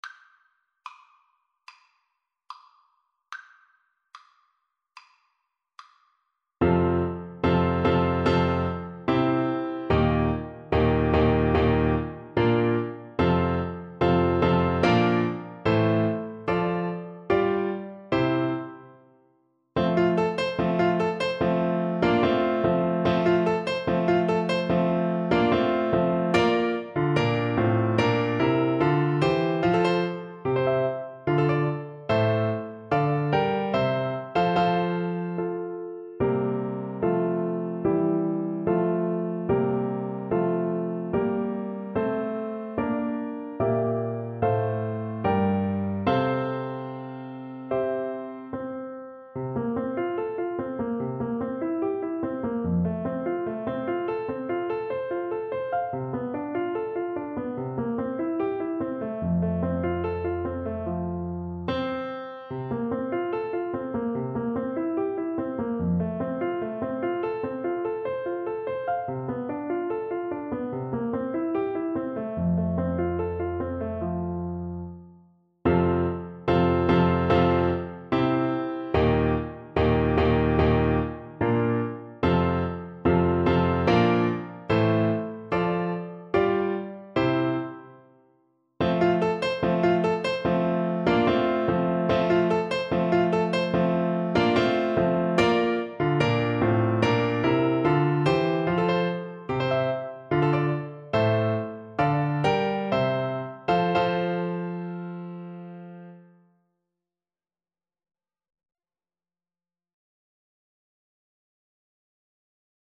Play (or use space bar on your keyboard) Pause Music Playalong - Piano Accompaniment Playalong Band Accompaniment not yet available transpose reset tempo print settings full screen
Flute
F major (Sounding Pitch) (View more F major Music for Flute )
Allegro guerriero =100 (View more music marked Allegro)
4/4 (View more 4/4 Music)
Classical (View more Classical Flute Music)